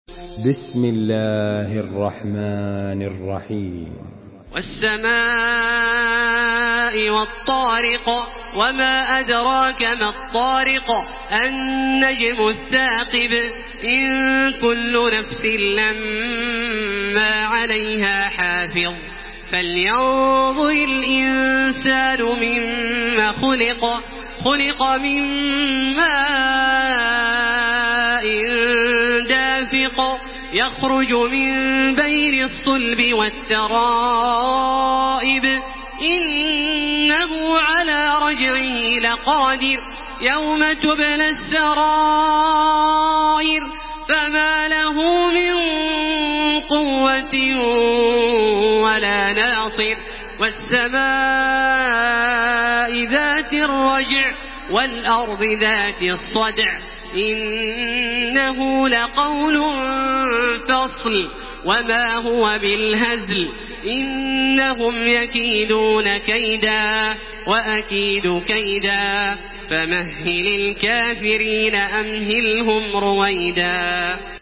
Surah At-Tariq MP3 by Makkah Taraweeh 1433 in Hafs An Asim narration.
Murattal Hafs An Asim